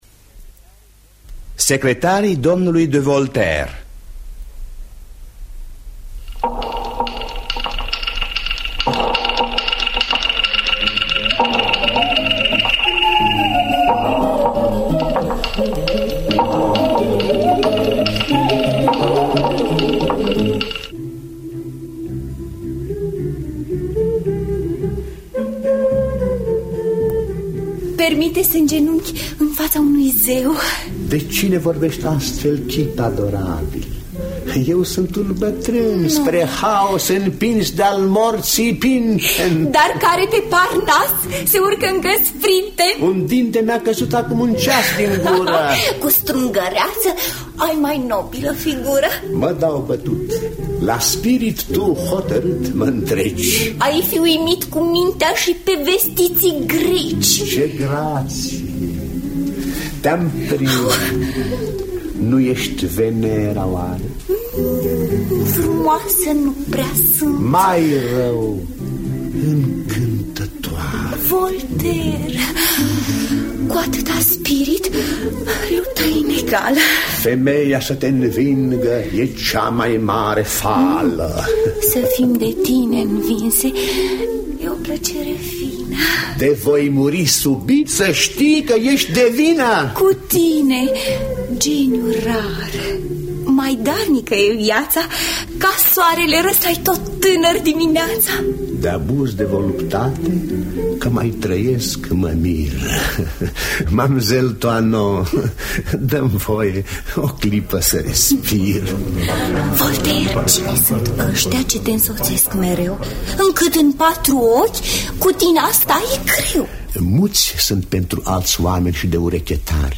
Secretarii domnului de Voltaire de George Călinescu – Teatru Radiofonic Online